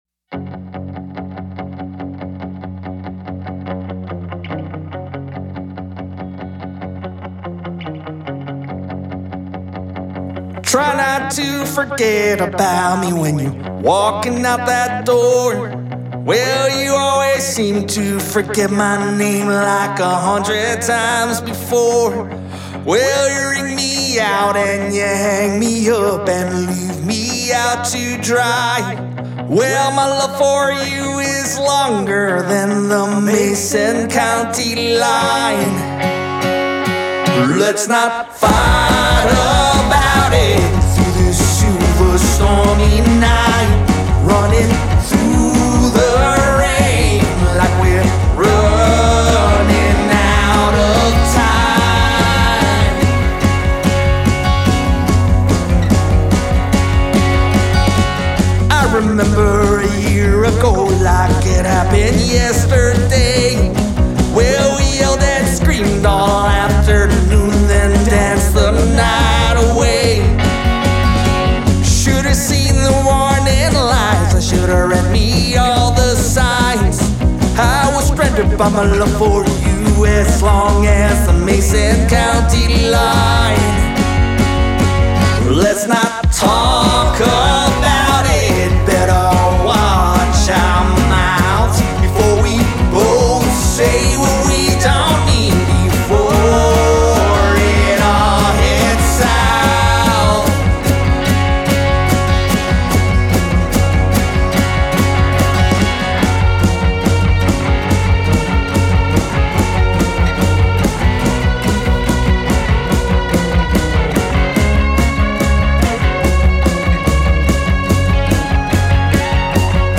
Soul-tinged Country Rock
vocals, guitar, drums
bass guitar
Melodic, rowdy & tender songs swathed in reverb.